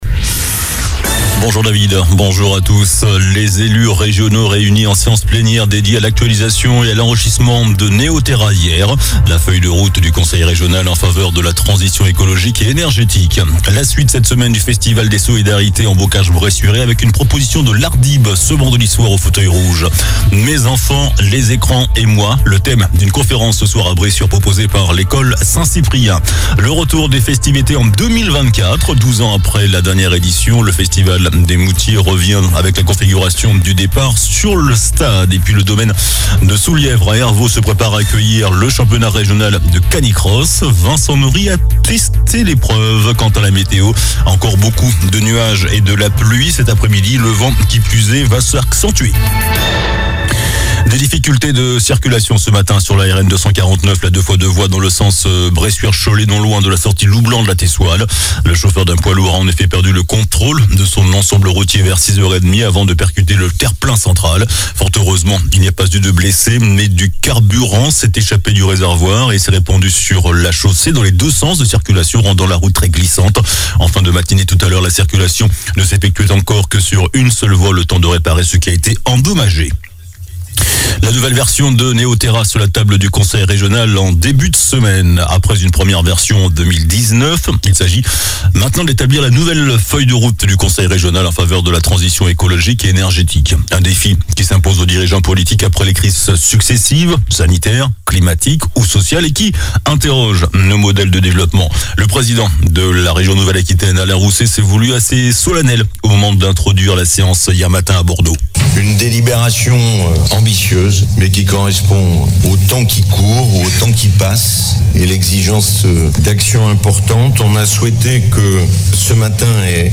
JOURNAL DU MARDI 14 NOVEMBRE ( MIDI )